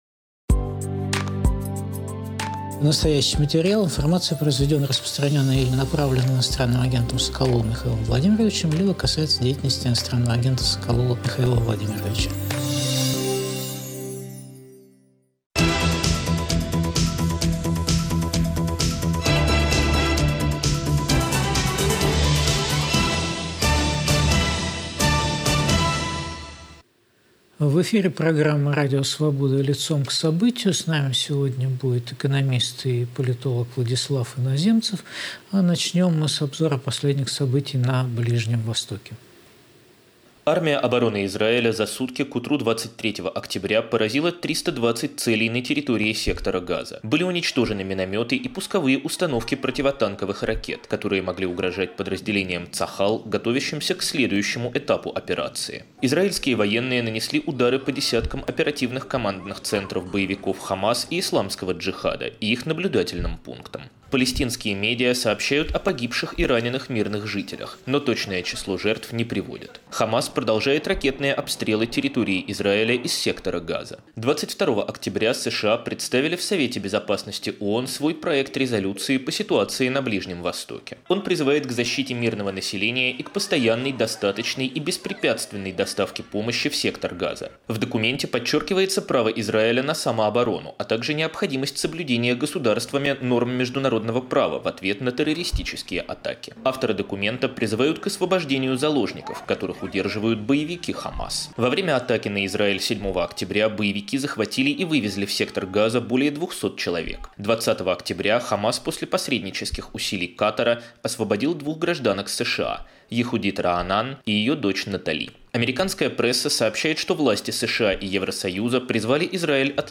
Каковы новые эффекты атаки на Израиль? В эфире Владислав Иноземцев.